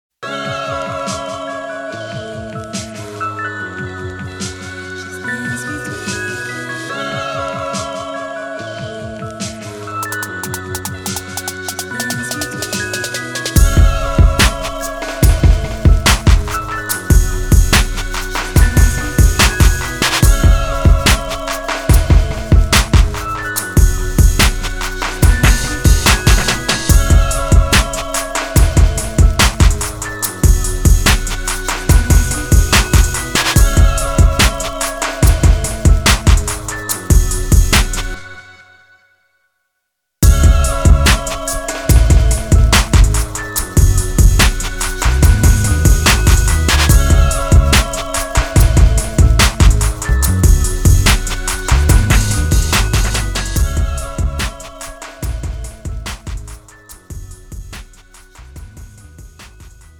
장르 pop 구분 Premium MR